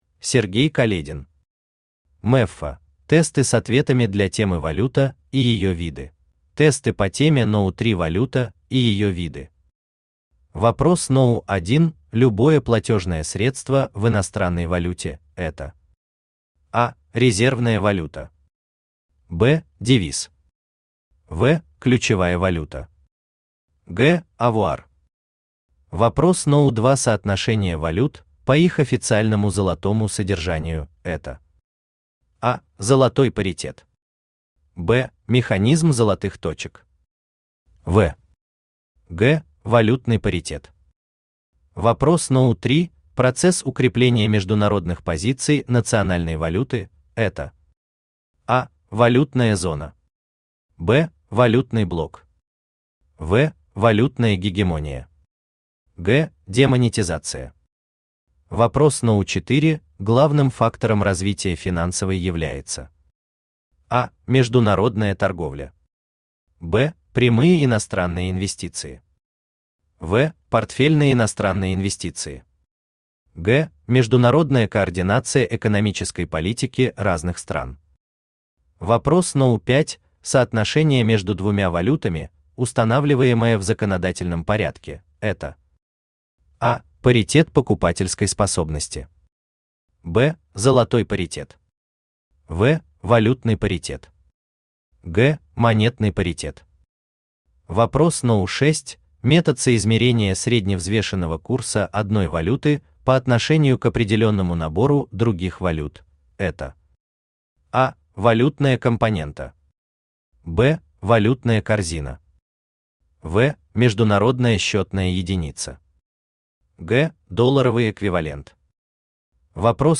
Тесты с ответами для темы «Валюта и её виды» Автор Сергей Каледин Читает аудиокнигу Авточтец ЛитРес.